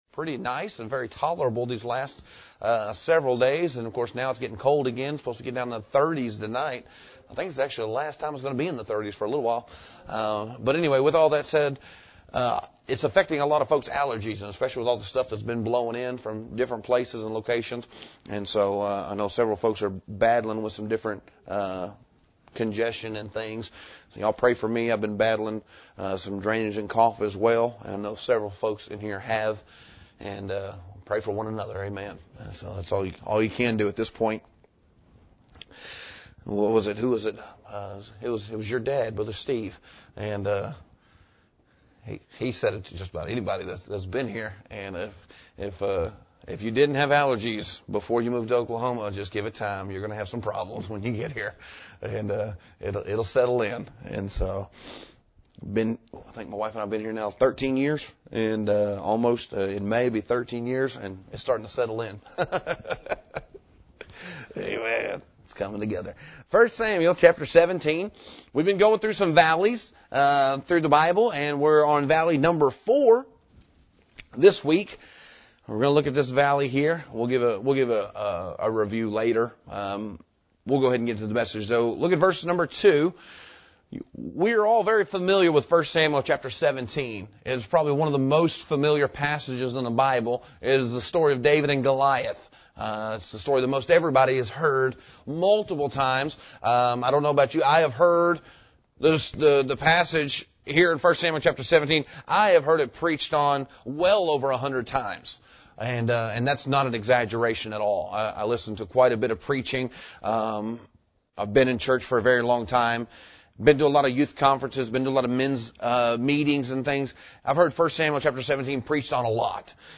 preaches from this passage on the topic of the Valley of Doubt